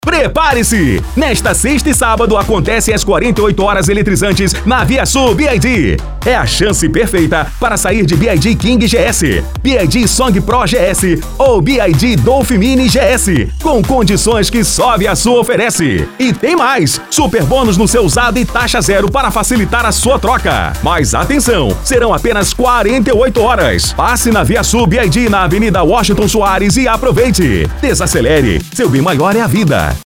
Spot Comercial
Impacto
Animada
Caricata